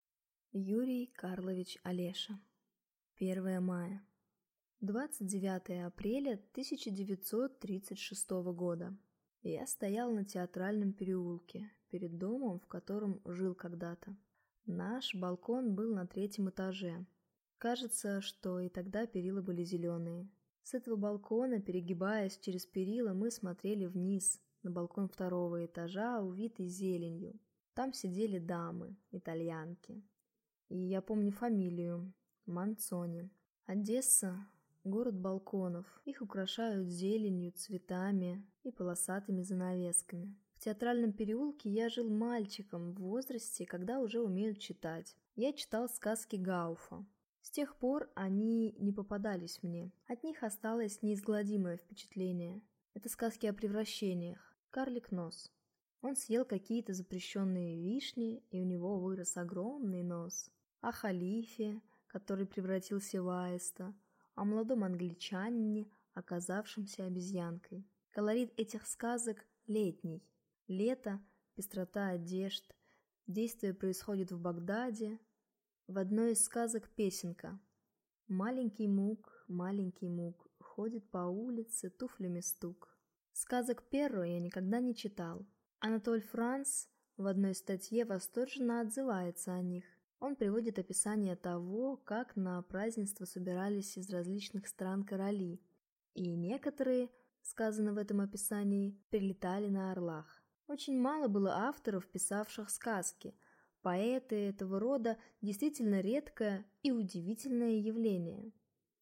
Аудиокнига Первое мая | Библиотека аудиокниг